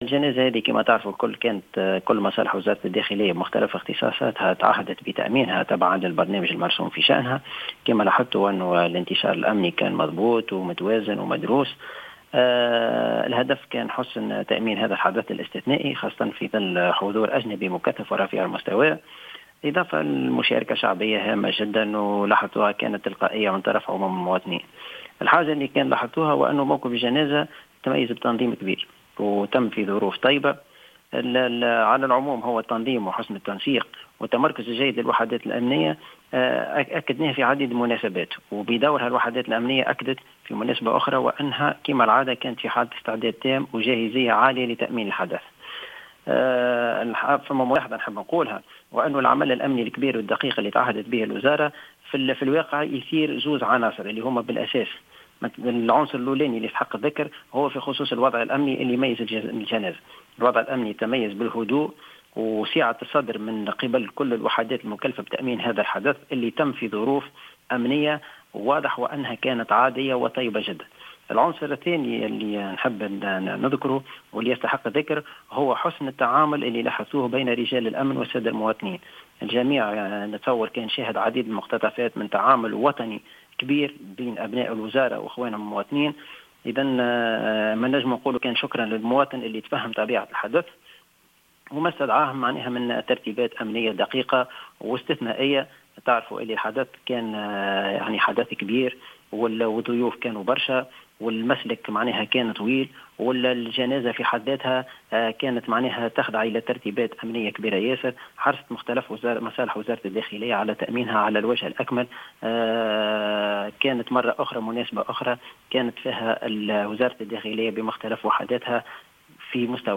تصريح لـ "الجوهرة اف أم"